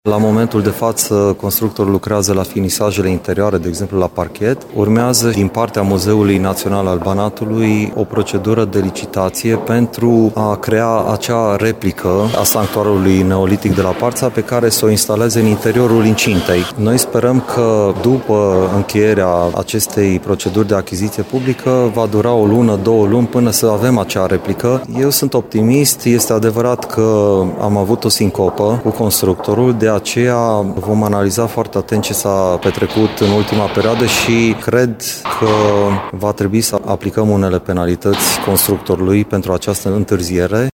Administratorul public al județului, Marian Vasile.